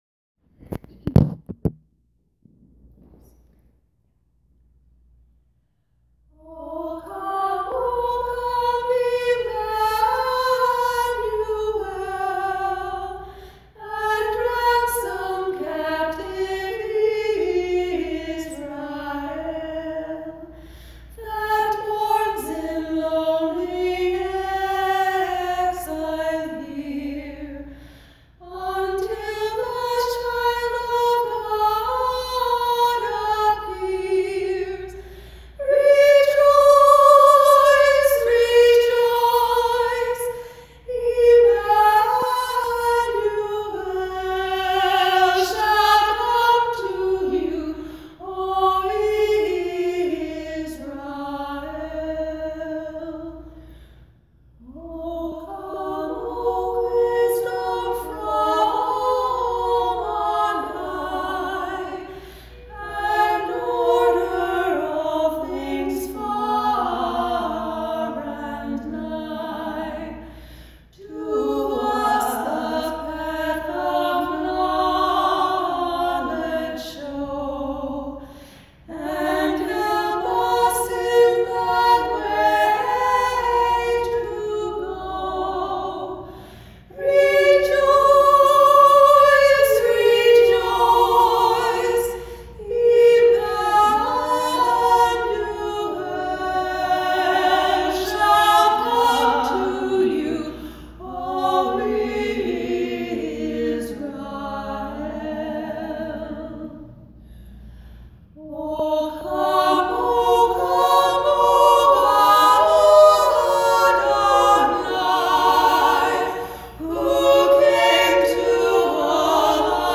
In 2015, I was lucky enough to sing in an acapella (I NEVER can spell that right!) trio for a pre-Christmas church service, and the blend was so lovely that I am posting our rehearsal, which thrilled all of us, as you can see by the excited outburst at the end of the MP3. The song is the classic carol, “O Come, O Come, Emmanuel”.
oh-come-emmanuel-trio.m4a